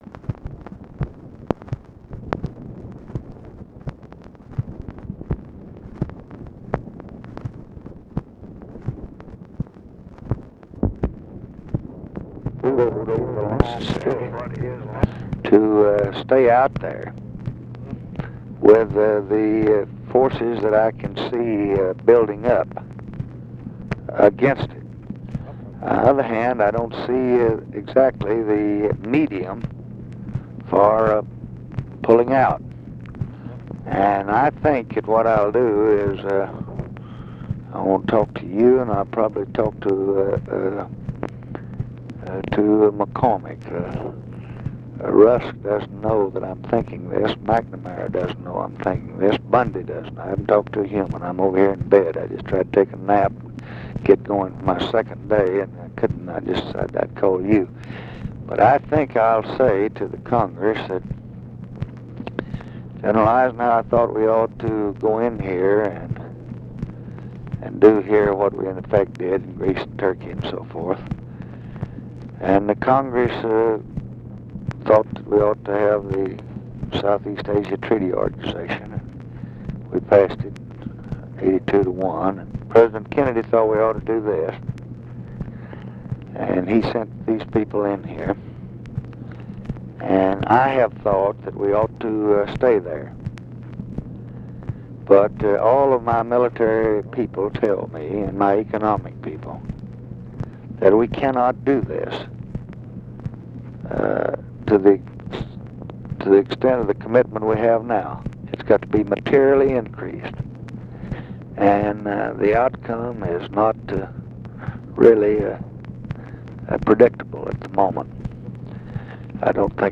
Conversation with MIKE MANSFIELD, June 8, 1965
Secret White House Tapes